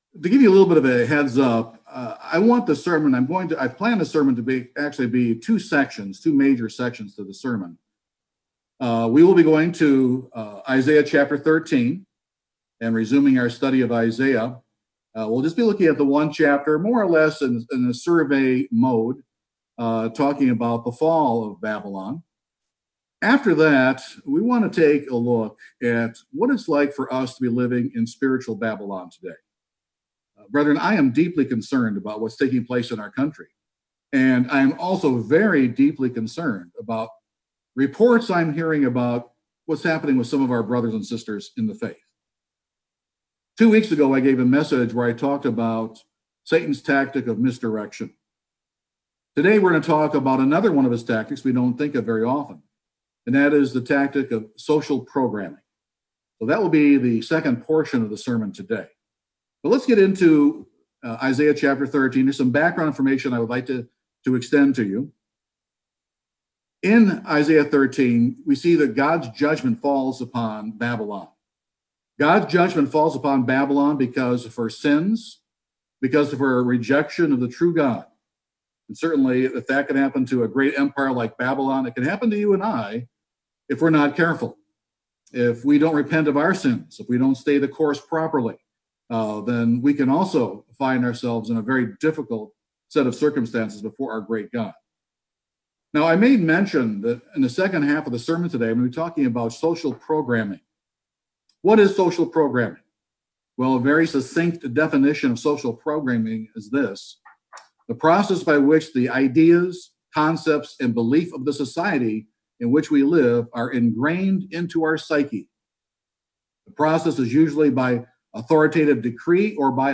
This sermon answers that question.